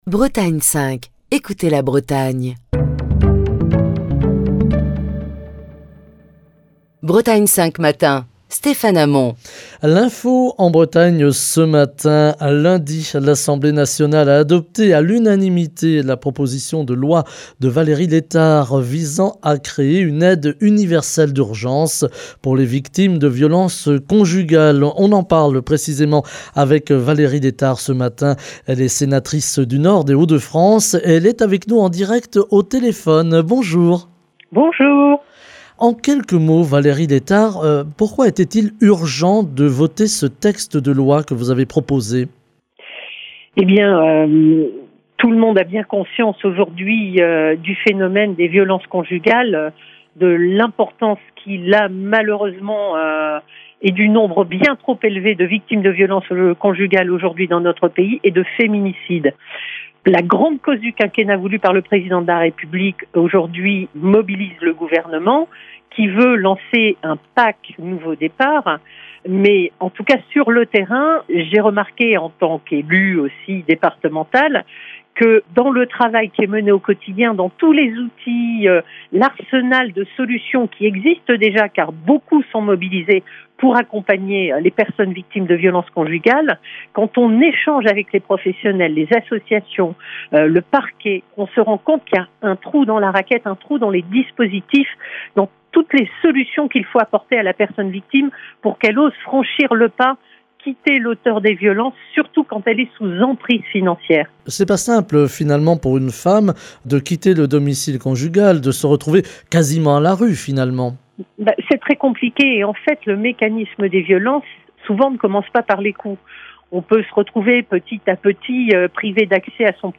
Lundi, l’Assemblée nationale a adopté à l’unanimité la proposition de loi de la Sénatrice Valérie Létard, visant à créer une aide financière universelle d’urgence pour les victimes de violences conjugales. Nous en parlons ce matin avec Valérie Létard, sénatrice du Nord (Hauts-de-France) qui est avec nous en direct au téléphone.